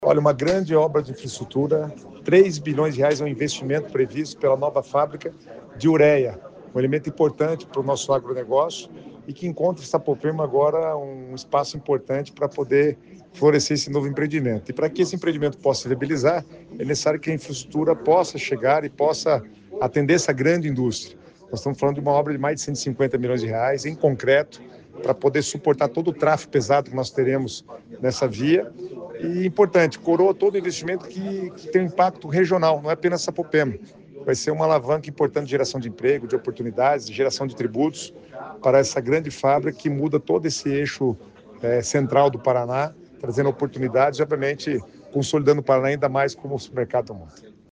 Sonora do secretário das Cidades, Guto Silva, sobre a rodovia de concreto em Sapopema